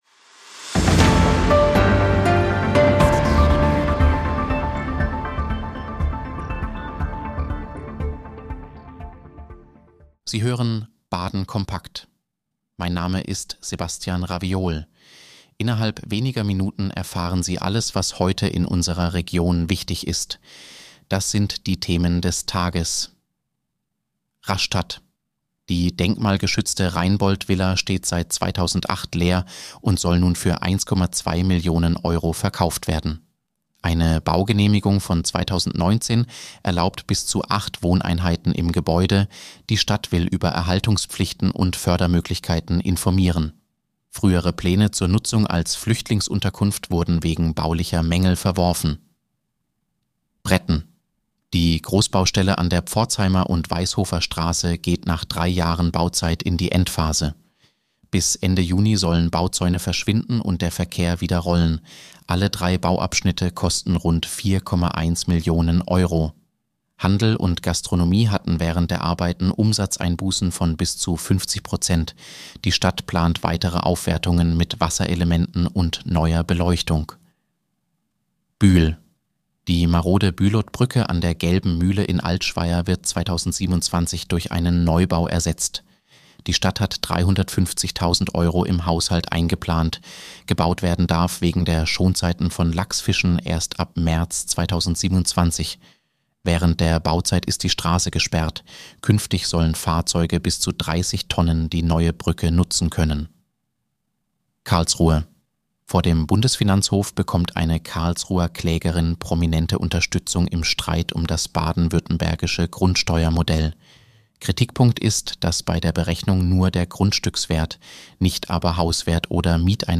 Nachrichtenüberblick: Verkauf der Rheinboldt Villa in Rastatt für 1,2 Millionen Euro